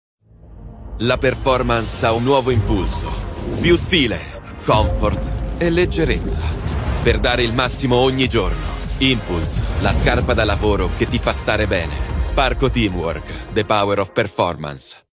Male
Confident, Engaging, Friendly, Natural, Versatile, Corporate, Deep, Young
Microphone: SE Electronics 2200A / Shure MV7